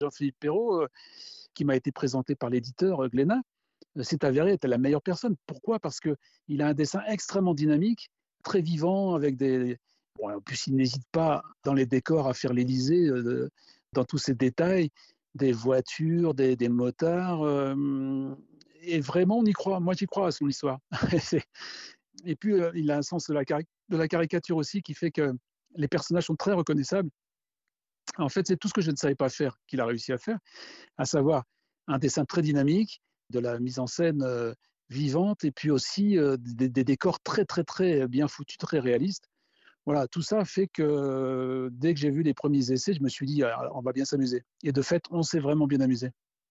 Entretien avec Didier Tronchet